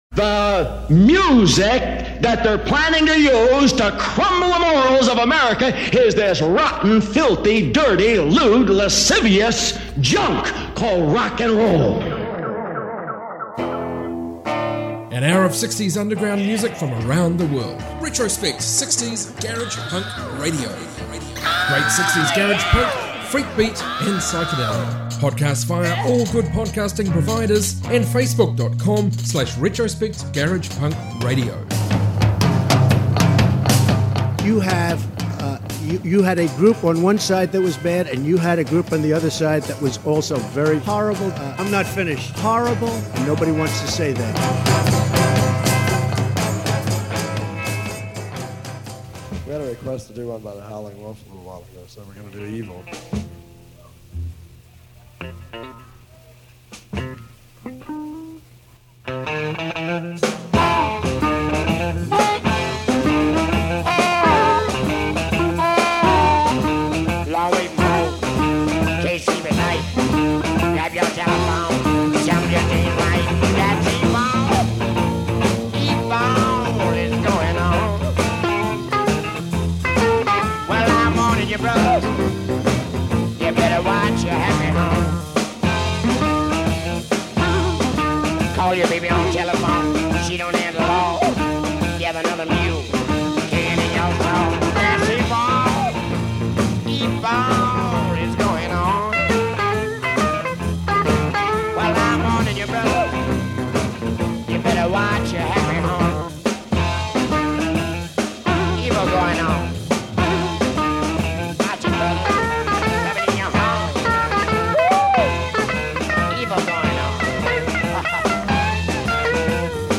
60s garage punk